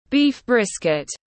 Gầu bò tiếng anh gọi là beef brisket, phiên âm tiếng anh đọc là /biːf ˈbrɪskɪt/